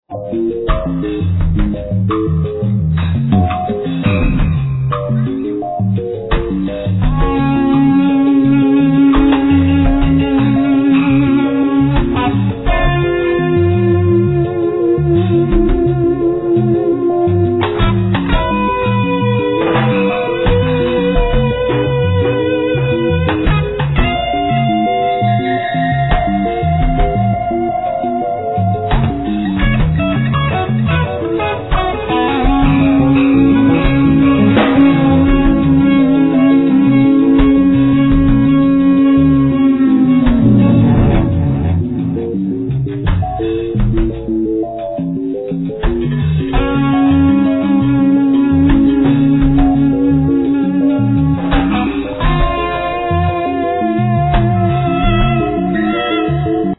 Drums
Bass
Alt sax
Percussions
Guitar, Effects